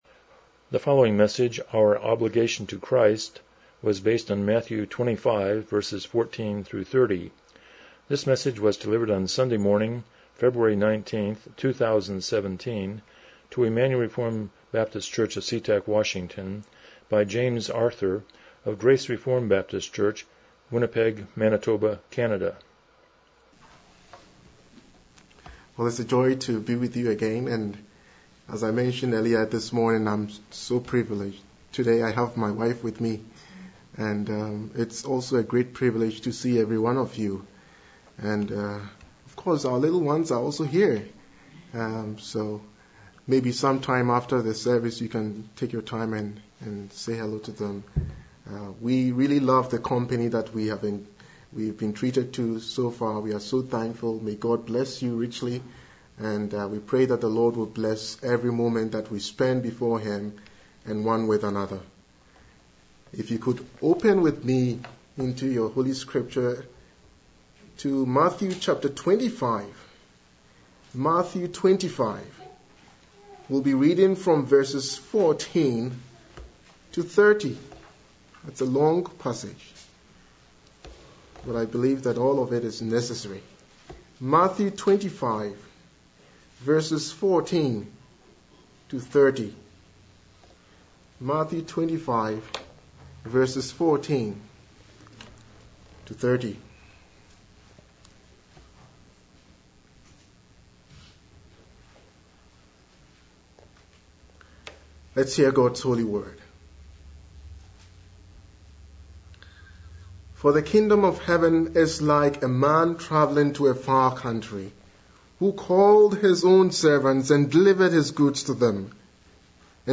Matthew 25:14-20 Service Type: Morning Worship « Christ’s Commission to His People